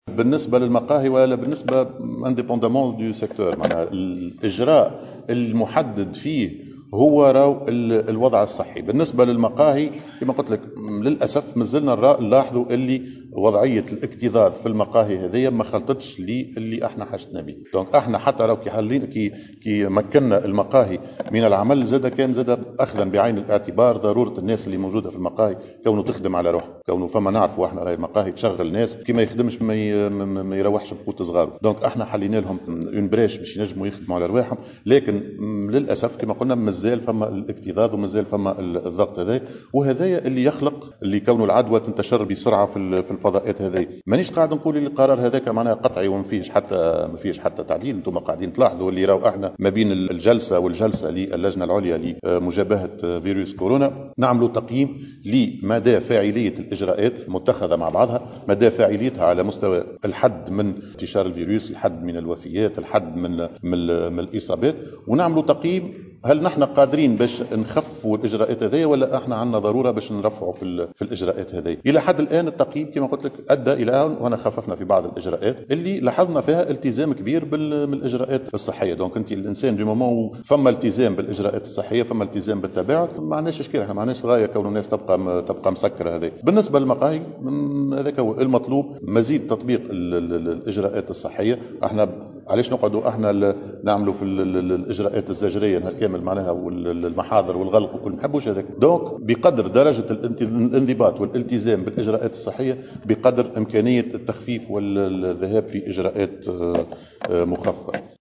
أكدّ رئيس الحكومة هشام المشيشي، خلال إشرافه اليوم بثكنة العوينة، على ندوة الوُلاّة أنّ إجراء غلق المقاهي عند الرابعة مساء، إجراء ضروري لأنّ هذه الفضاءات تشهد اكتظاظا وتساهم في نشر العدوى.